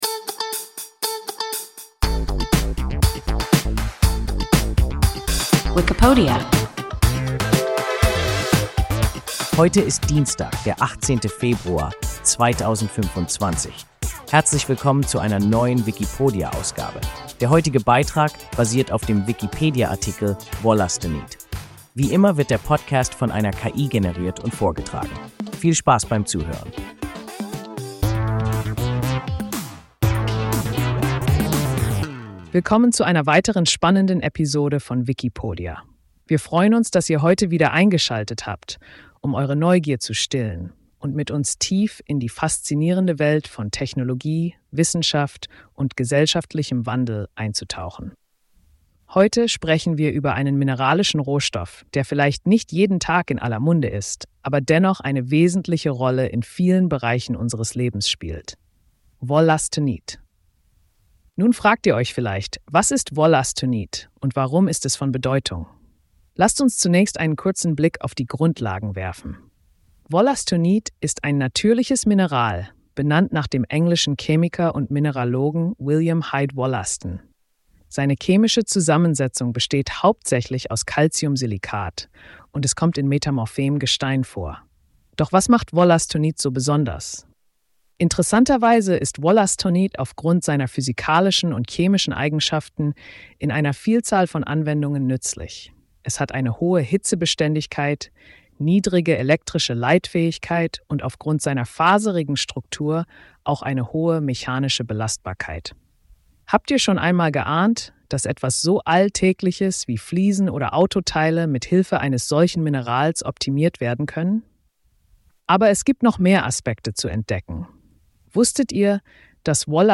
Wollastonit – WIKIPODIA – ein KI Podcast